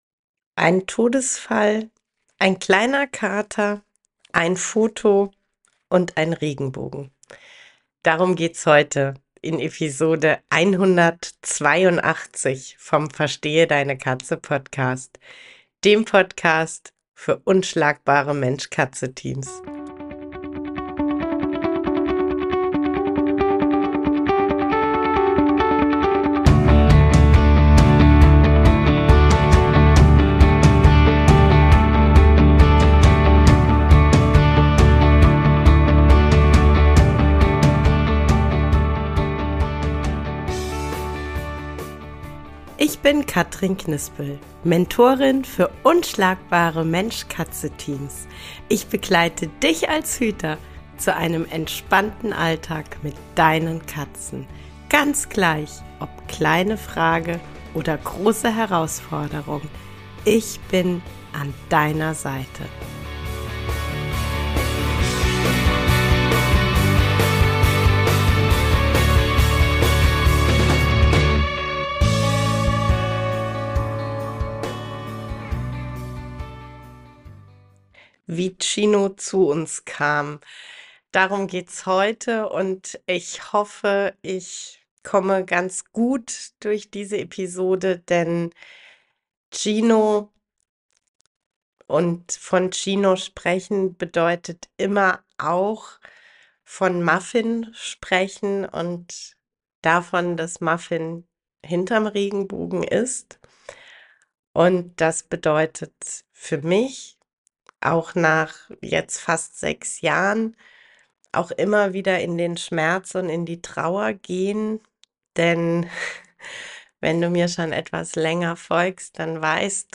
An der ein oder anderen Stellen sind ein paar Tränen geflossen.